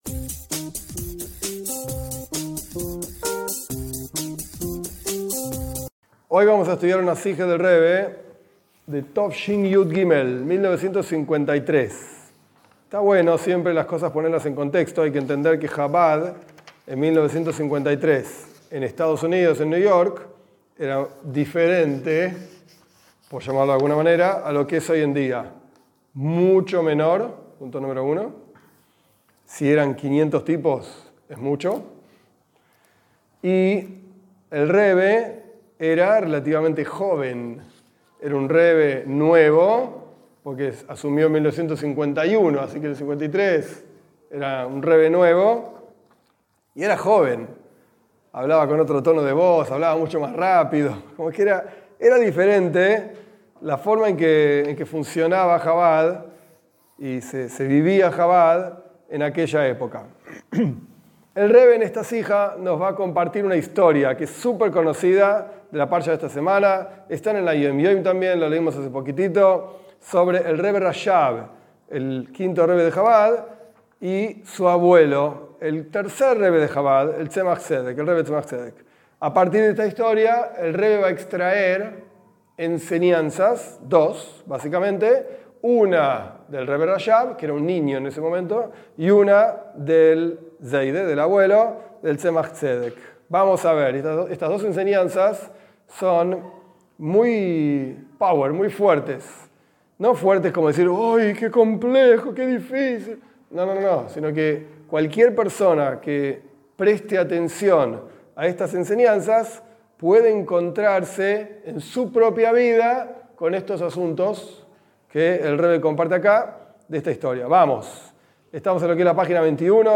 Esta es una clase sobre una charla del Rebe de parashat Vaierá, 1953. En esta charla el Rebe explica una historia que ocurrió con el Rebe Rashab, cuando tenía 4 o 5 años y lloró, frente a su abuelo, el tercer Rebe de Jabad, diciendo: ¿Por qué Di-s no se muestra a mí como se mostró a Avraham?